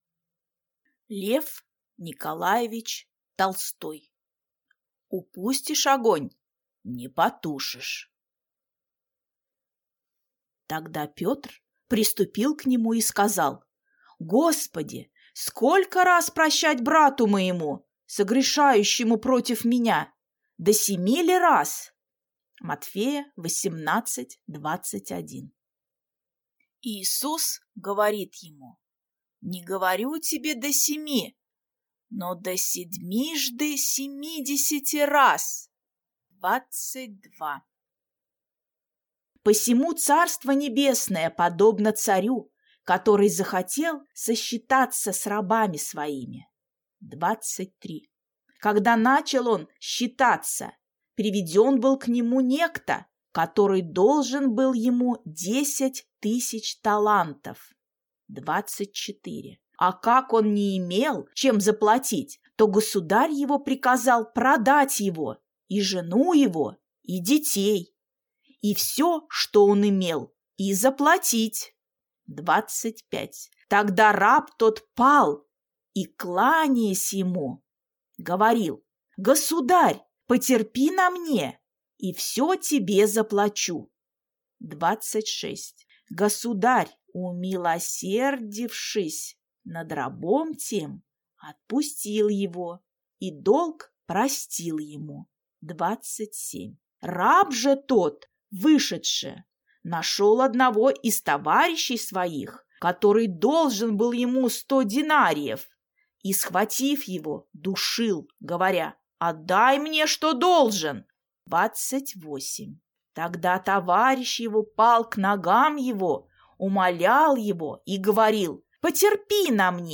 Аудиокнига Упустишь огонь – не потушишь | Библиотека аудиокниг